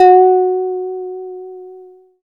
SYN HARPLI02.wav